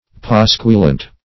Pasquilant \Pas"quil*ant\, n. A lampooner; a pasquiler.
pasquilant.mp3